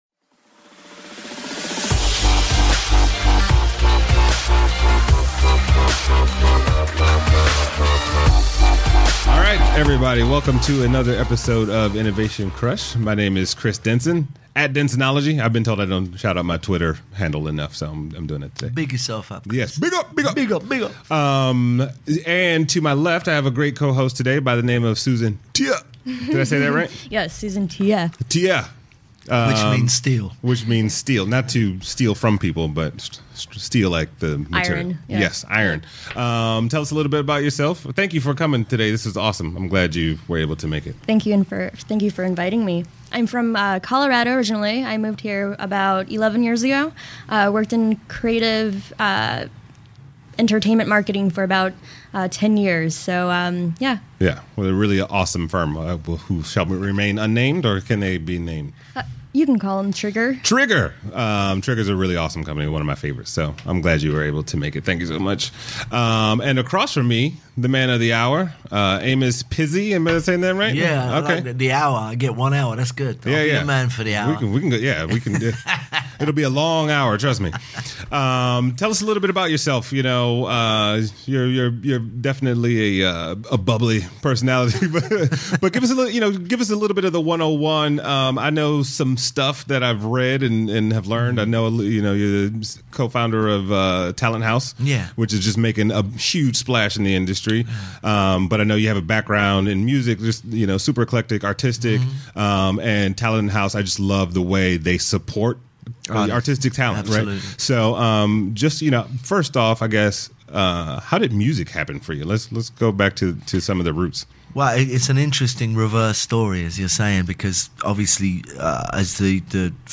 Features special guest host